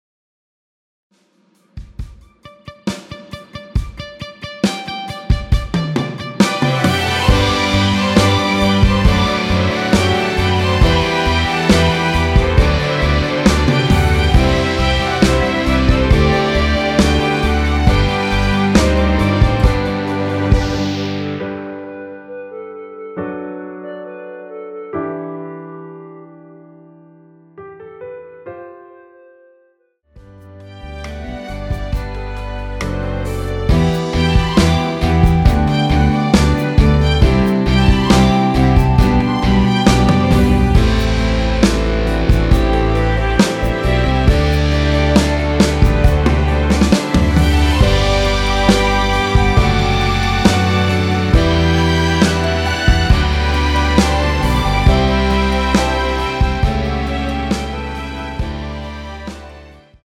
원키에서(+3)올린 멜로디 포함된 MR입니다.(미리듣기 확인)
앞부분30초, 뒷부분30초씩 편집해서 올려 드리고 있습니다.
중간에 음이 끈어지고 다시 나오는 이유는